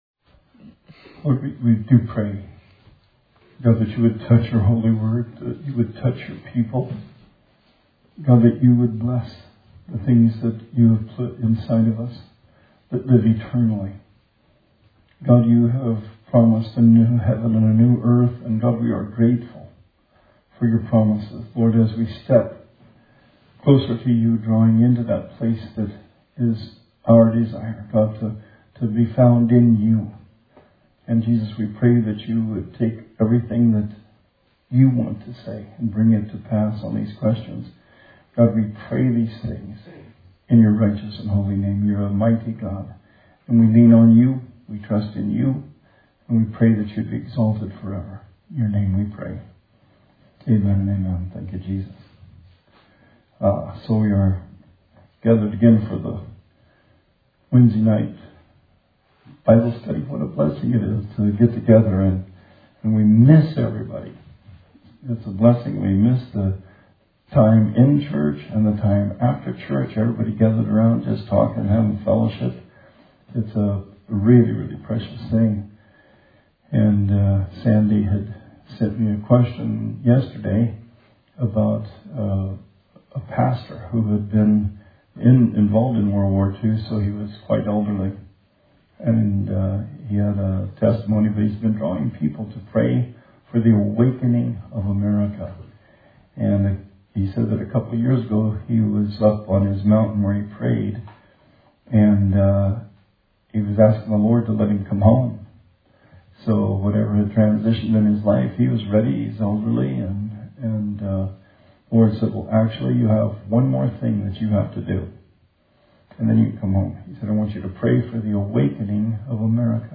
Bible Study 5/6/20